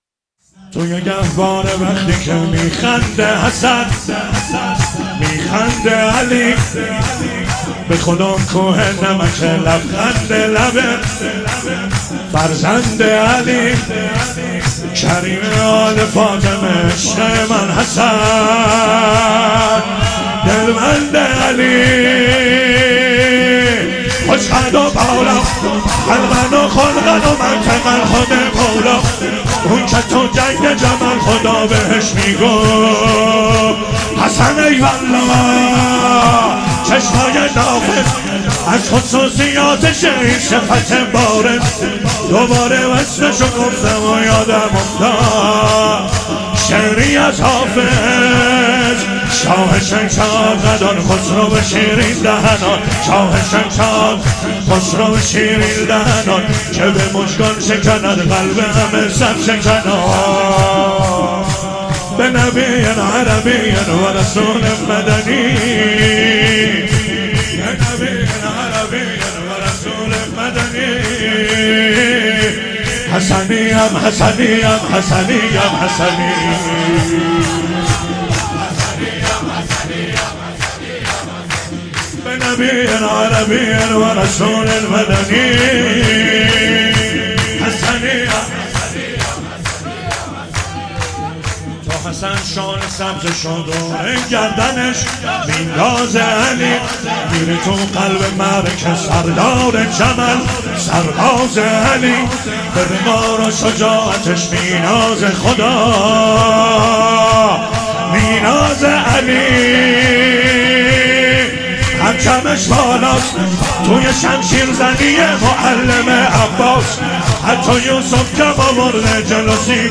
مراسم هفتگی نهم اردیبهشت۱۴۰۰
شور